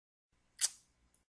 சொடுக்கிடும் வல்லினம்